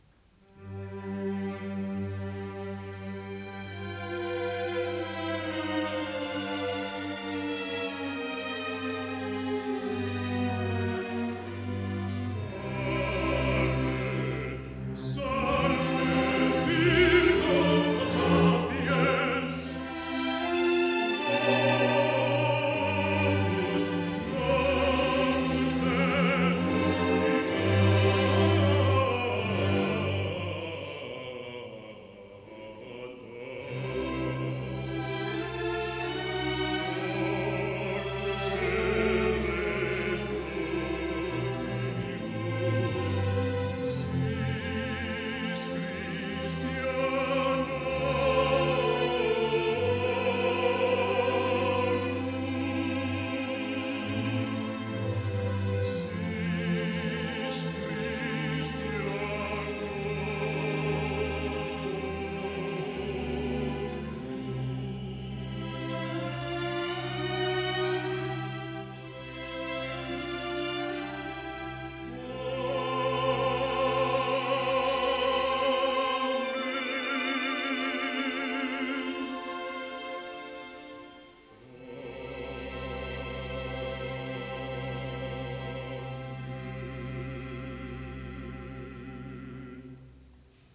barítono ; Orquesta de cuerda de la Euskadiko Orkestra